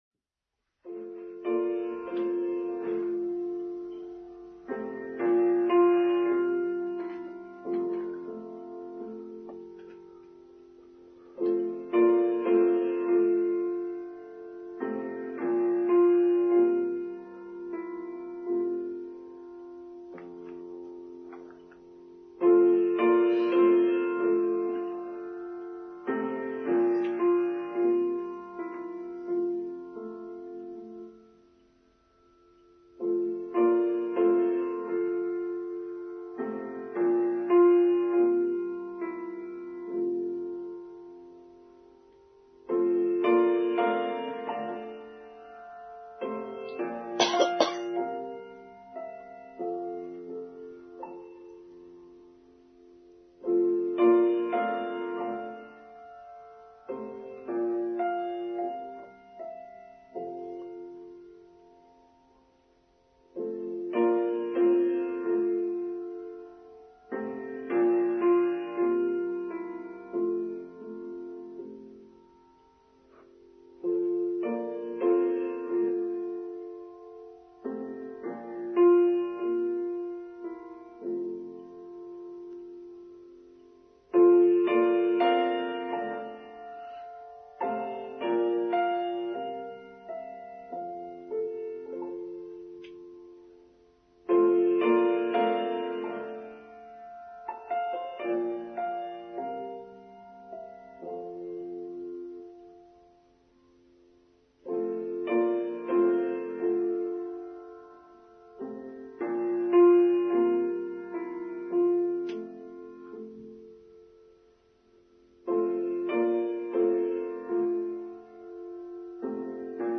Coming Back: Online Service for Sunday 30th May 2021